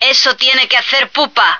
flak_m/sounds/female1/est/F1hadtohurt.ogg at 86e4571f7d968cc283817f5db8ed1df173ad3393